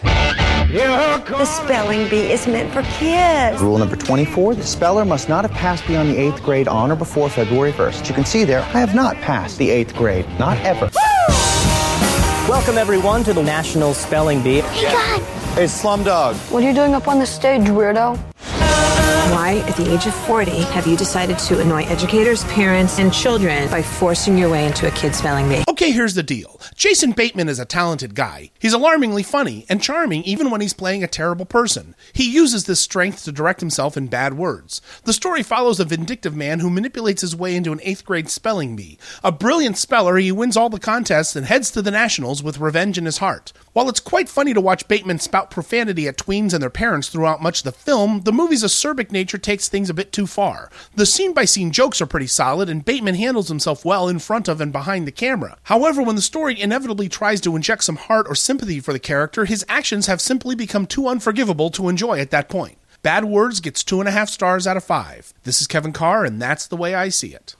‘Bad Words’ Movie Review